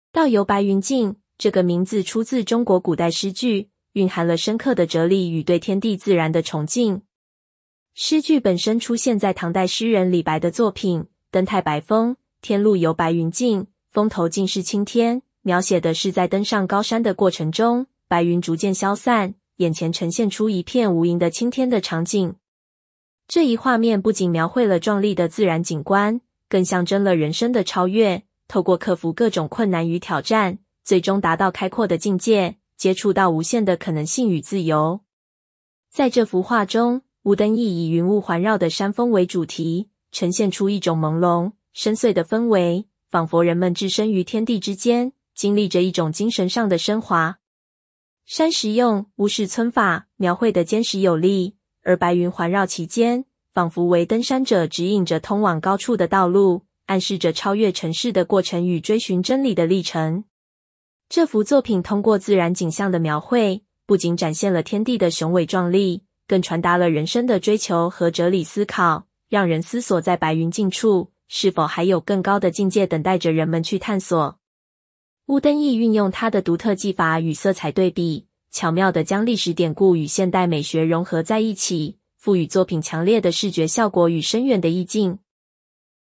中文語音導覽